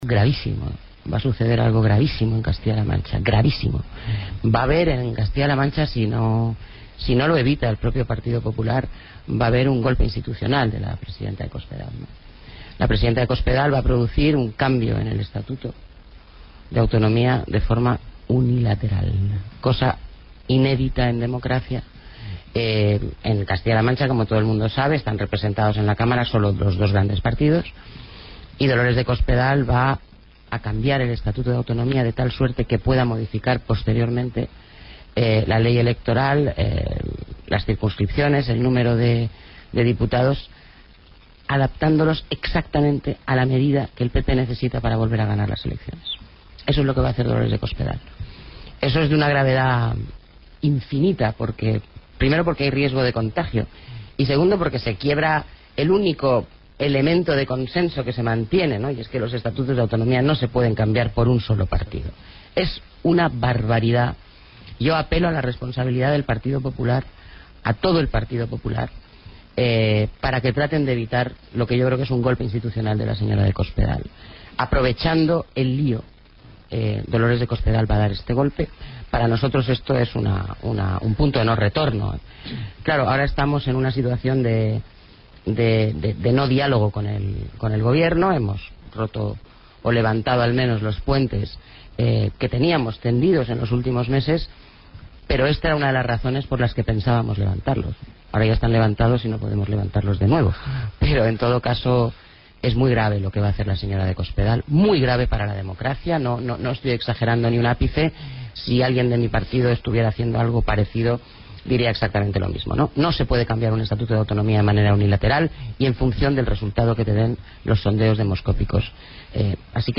Valenciano, en declaraciones a Rne, se ha sumado así a las críticas del PSOE a esa reforma, que permitirá reducir prácticamente a la mitad el número de diputados en Castilla-La Mancha.
Cortes de audio de la rueda de prensa
VALENCIANO_EN_RNE-1_-_EL_DIA_MENOS_PENSADO_SOBRE_ESTATUTO_C-LM.mp3